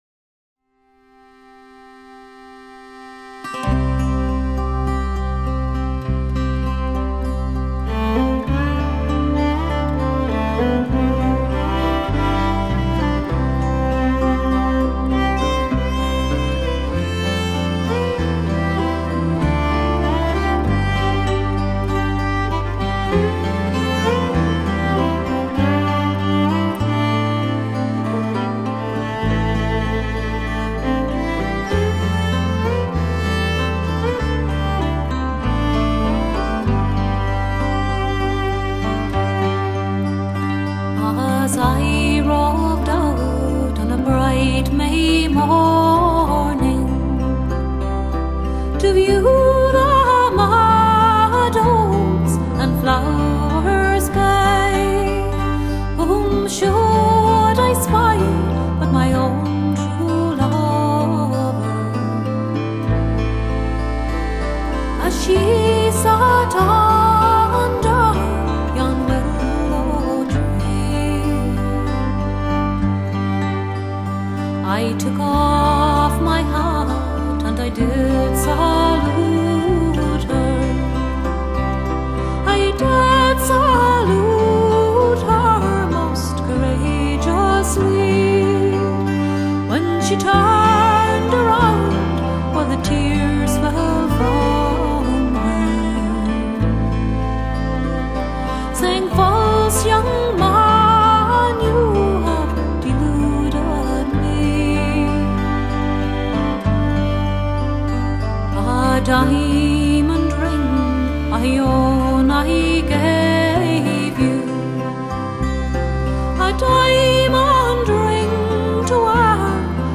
塞尔特音乐
挚爱、憧憬与祈祷的音乐氛围 亘古流传的永恒歌曲
浓郁、淡雅的情愫在歌声音符间萦回流转